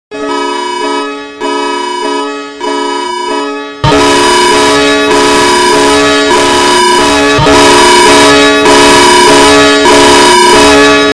China EAS alarm (1862)